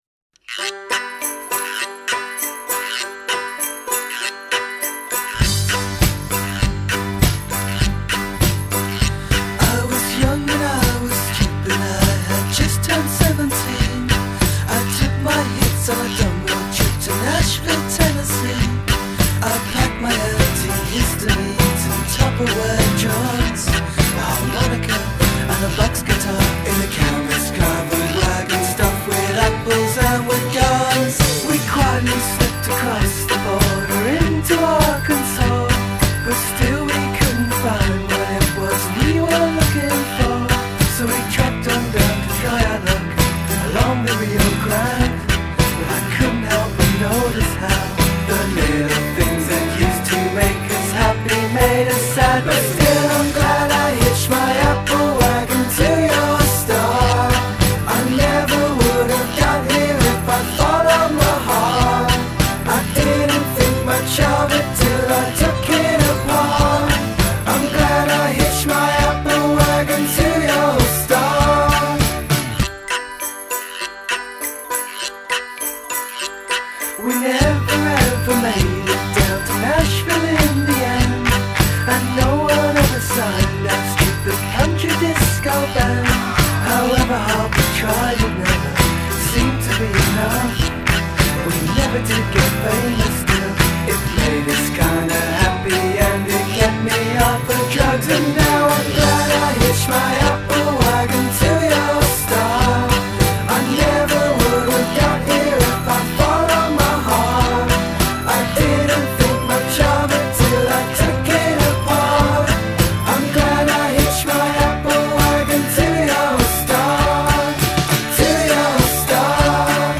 I’m still trying to wrap my head around country disco.